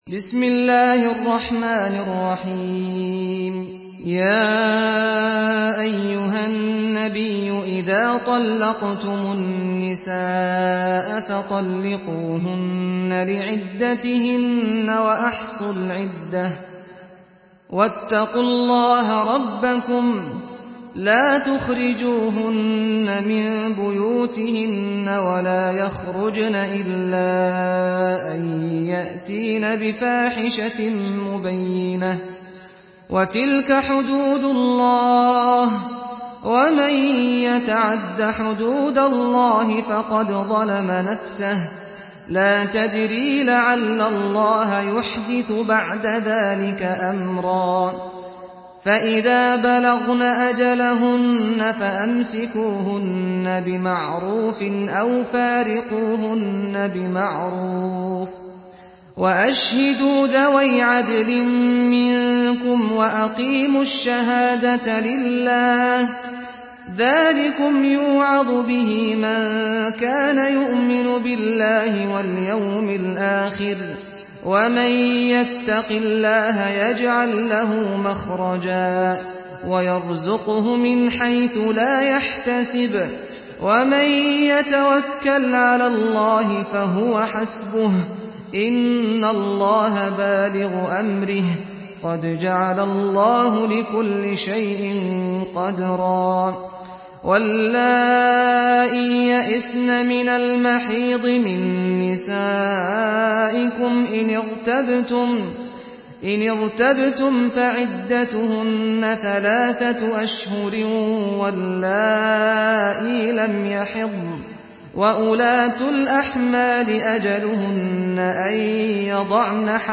Surah আত-ত্বালাক MP3 by Saad Al-Ghamdi in Hafs An Asim narration.
Murattal Hafs An Asim